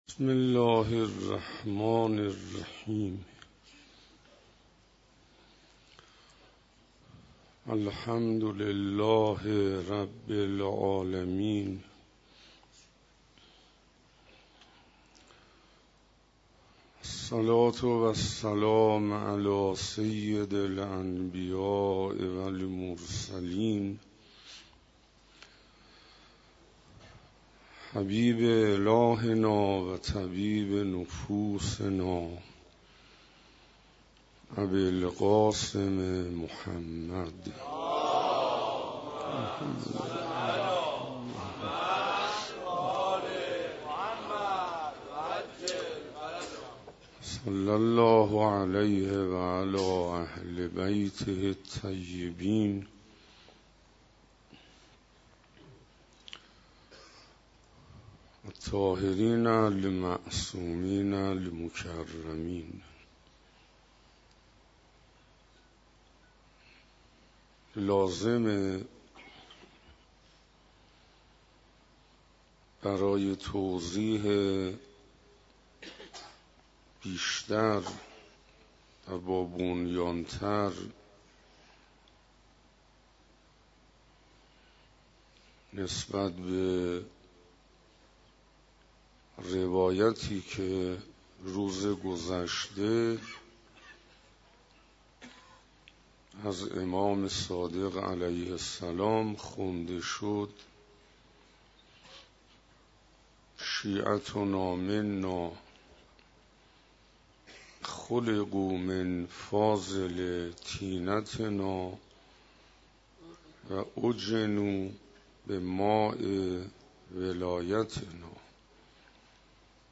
صوت کامل این سخنرانی را اینجا بشنوید: